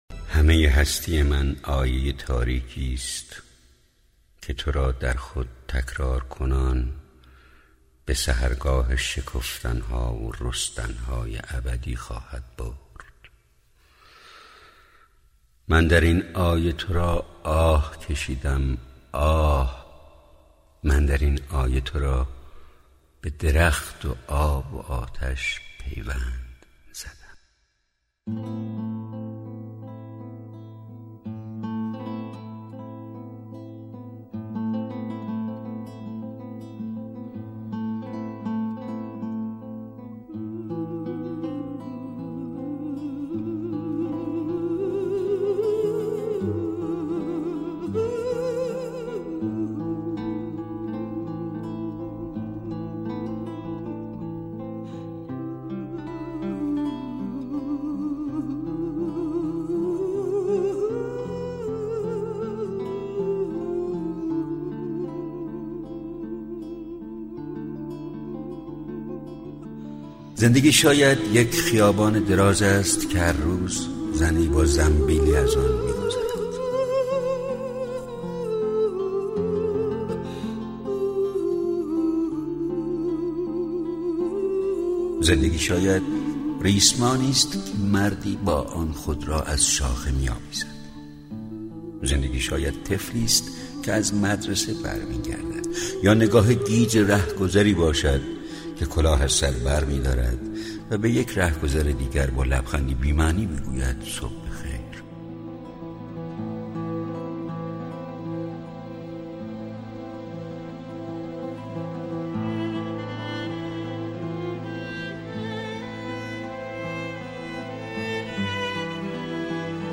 دانلود دکلمه تولدی دیگر باصدای خسرو شکیبایی با متن دکلمه